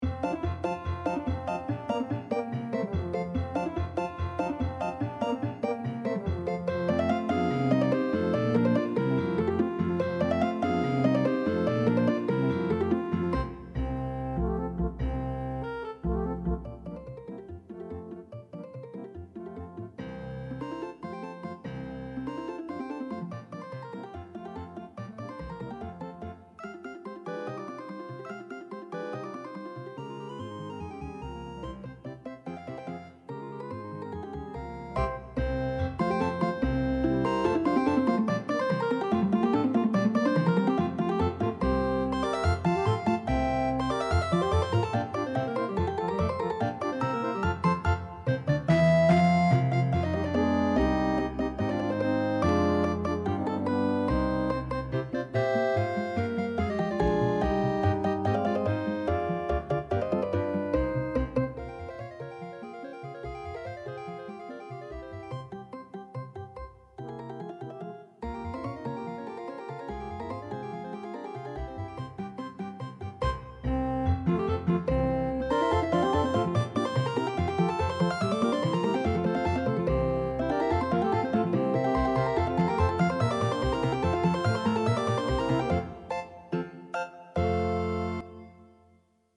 for wind octet